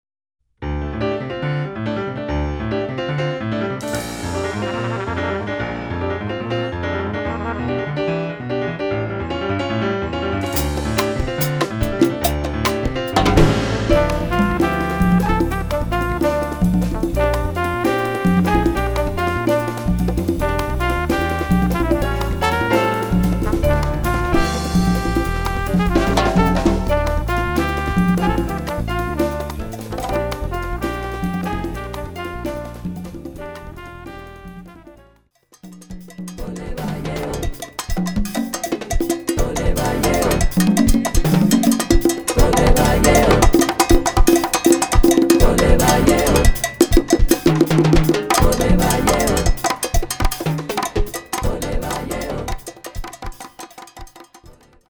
Category: combo (quintet)
Style: mambo
Solos: open
Instrumentation: combo (quintet) trumpet, rhythm (4)